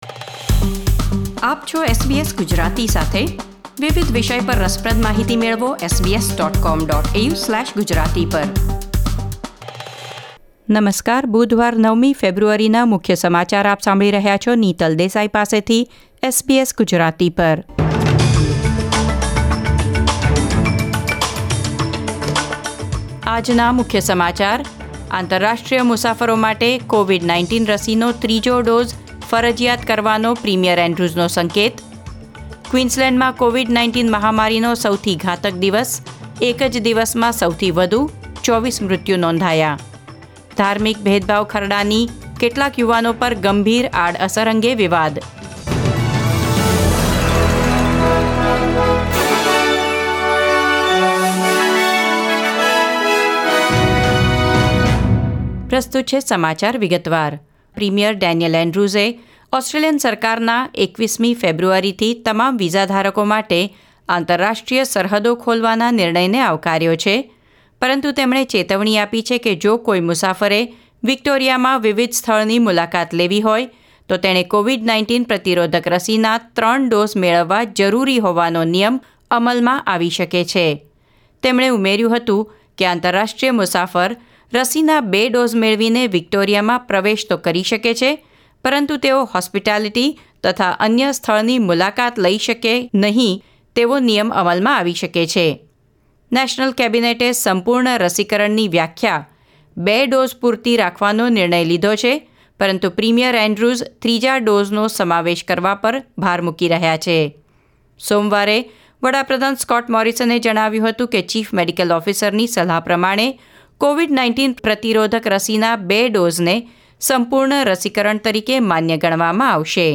SBS Gujarati News Bulletin 9 February 2022